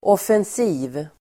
Uttal: [åfens'i:v (el. 'åf:-)]